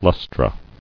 [lus·tra]